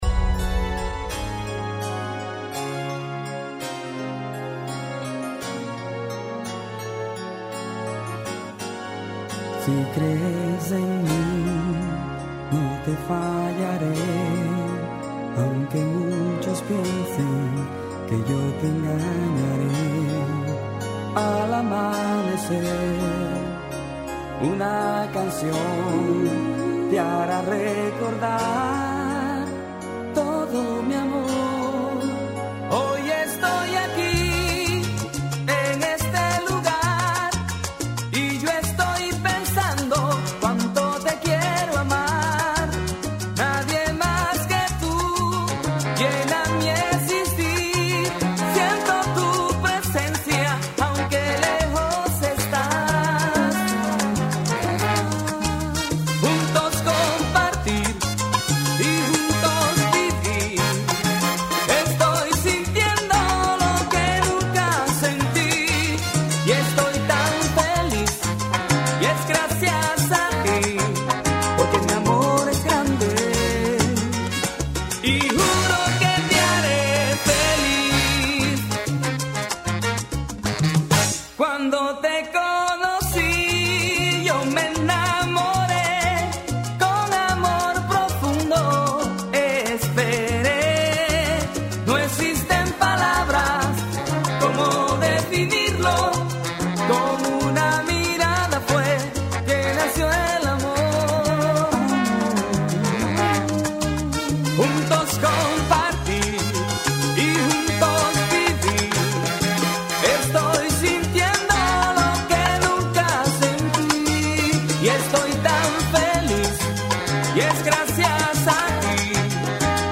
Mixes